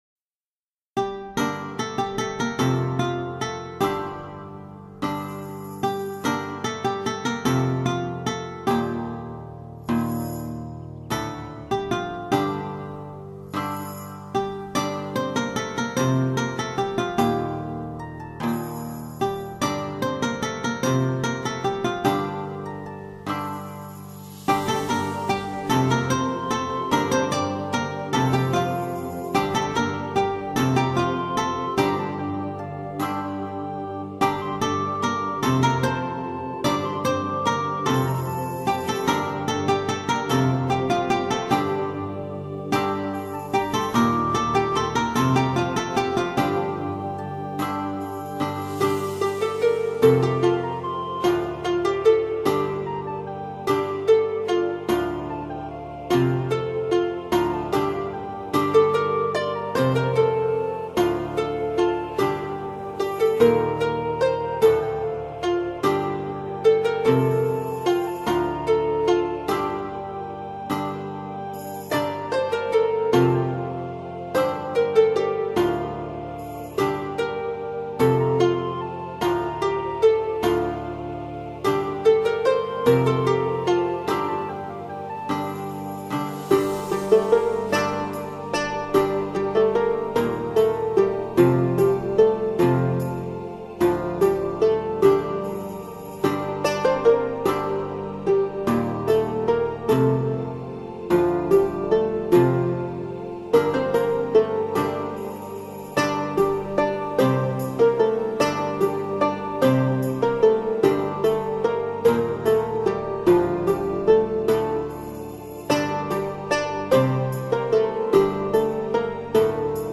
medieval_musique3.mp3